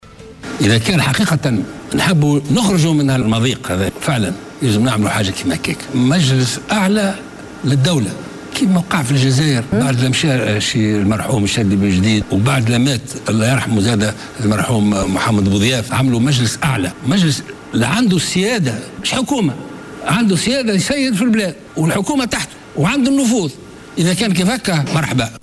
أكد رئيس حركة نداء تونس الباجي قايد السبسي مساء اليوم في حوار له على قناة نسمة ضرورة انشاء مجلس اعلى لدولة للخروج بالبلاد من الازمة الحالية.